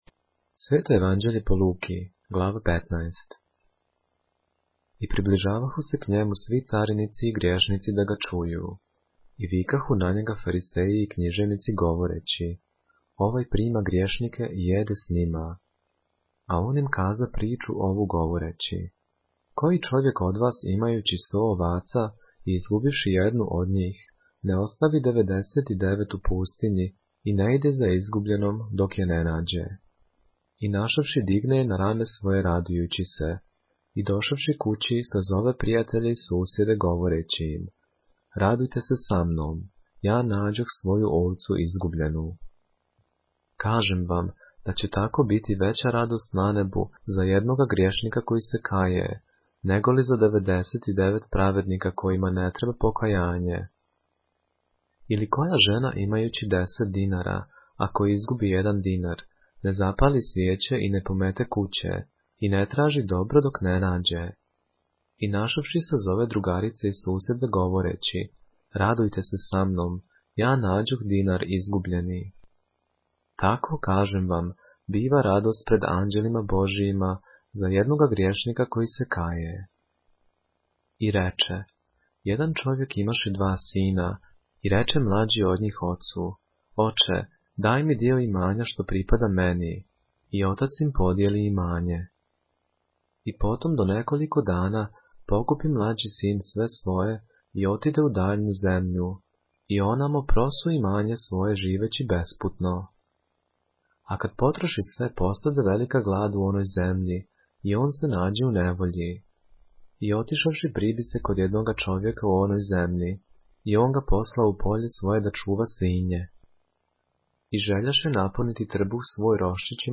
поглавље српске Библије - са аудио нарације - Luke, chapter 15 of the Holy Bible in the Serbian language